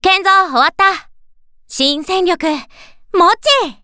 Ship_Voice_Fujinami_Kai_Ni_Construction.mp3